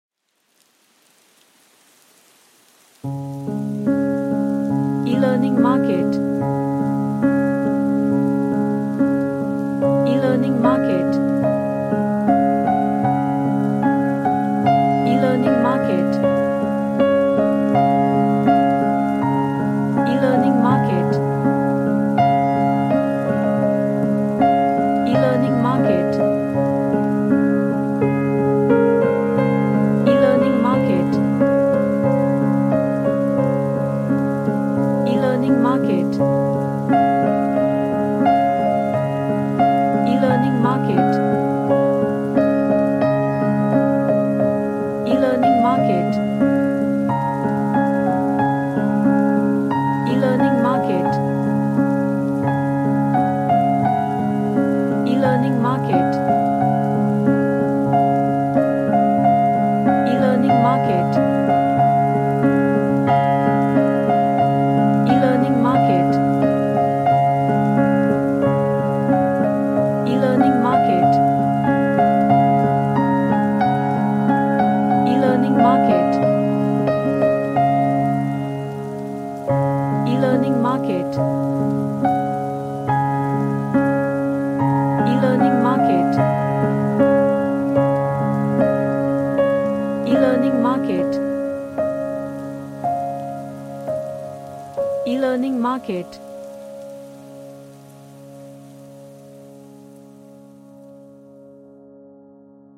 A cheerfull and happy sounding piano track
Happy / Cheerful